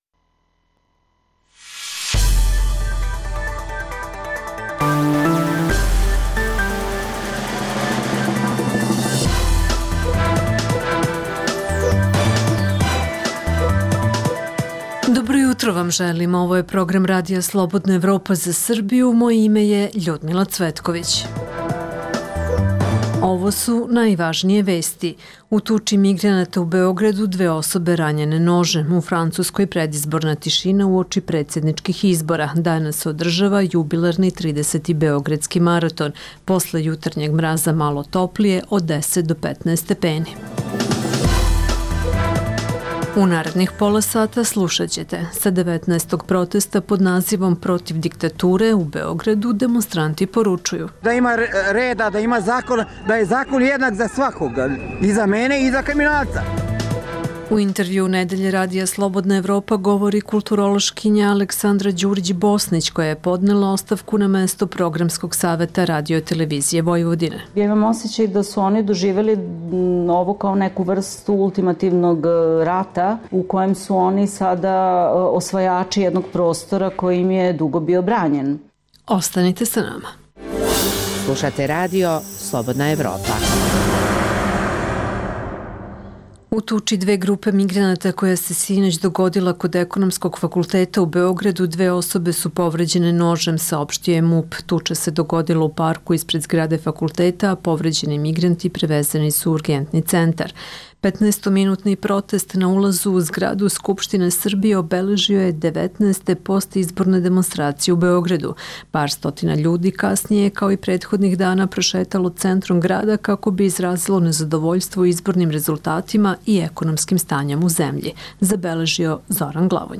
Pored dnevnih aktuelnosti slušaćete i Intervju nedelje RSE.